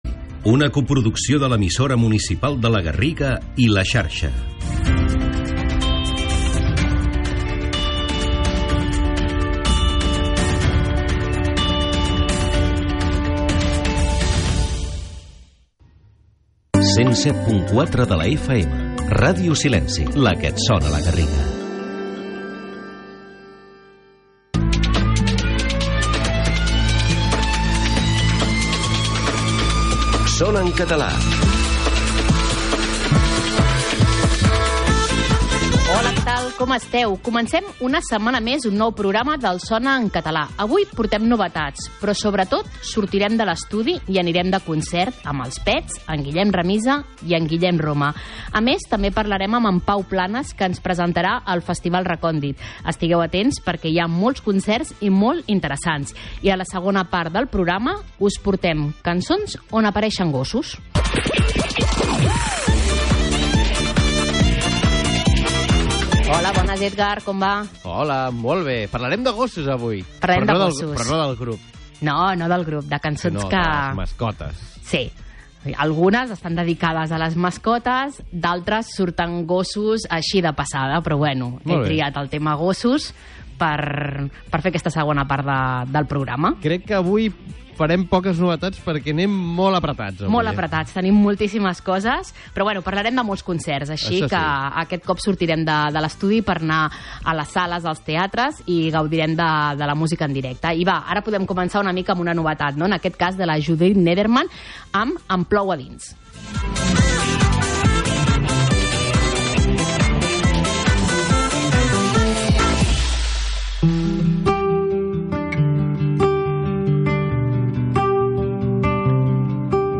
Una hora de música en català amb cançons de tots els temps i estils. Història, actualitat, cròniques de concerts, curiositats, reportatges, entrevistes...